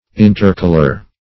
intercalar - definition of intercalar - synonyms, pronunciation, spelling from Free Dictionary
Intercalar \In*ter"ca*lar\, a.